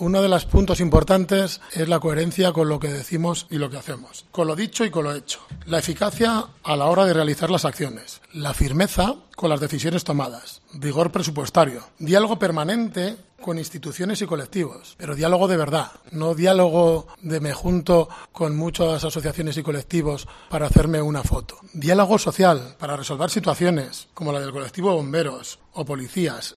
Iván Reinares, portavoz del Grupo Municipal Socialista de Logroño